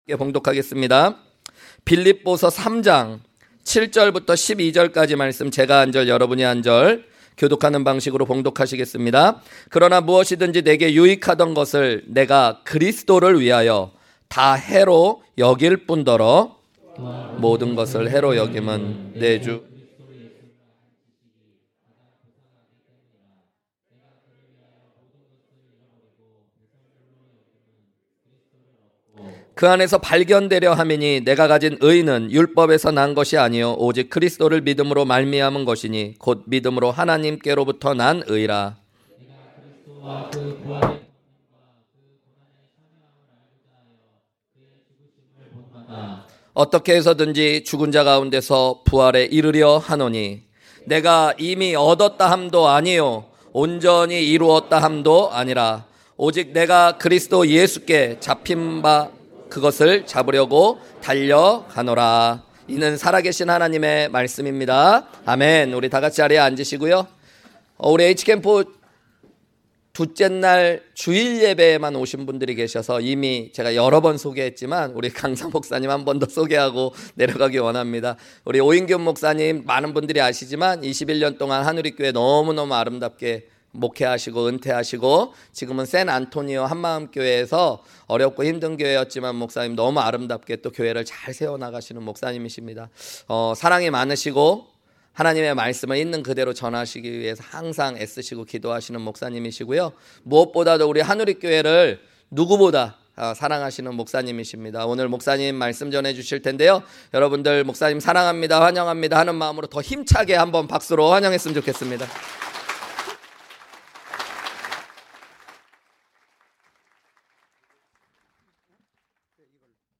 주일 외 설교
2022 H-Camp 둘째날 주일 오전 설교